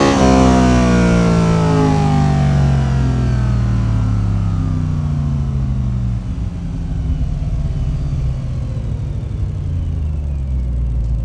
rr3-assets/files/.depot/audio/Vehicles/v8_02/v8_02_Decel.wav
v8_02_Decel.wav